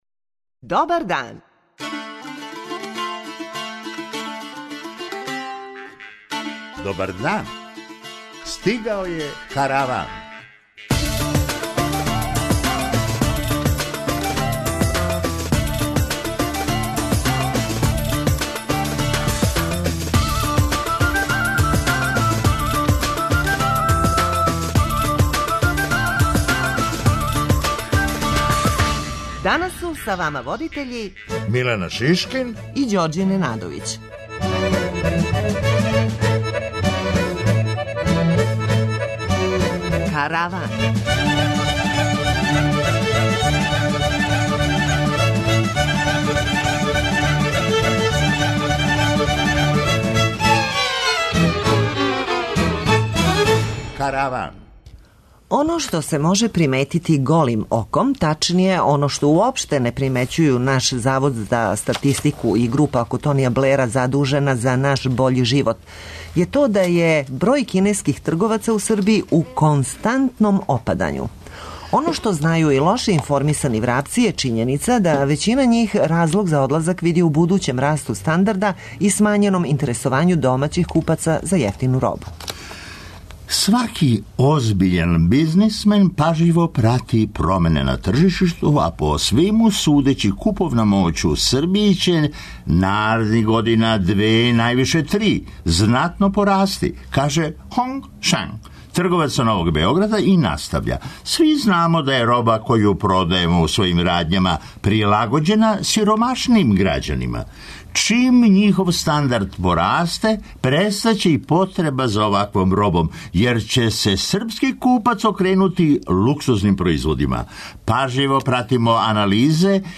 преузми : 23.20 MB Караван Autor: Забавна редакција Радио Бeограда 1 Караван се креће ка својој дестинацији већ више од 50 година, увек добро натоварен актуелним хумором и изворним народним песмама. [ детаљније ] Све епизоде серијала Аудио подкаст Радио Београд 1 Подстицаји у сточарству - шта доносе нове мере Хумористичка емисија Хумористичка емисија Корак ка науци Афера Епстин "не пушта" британског премијера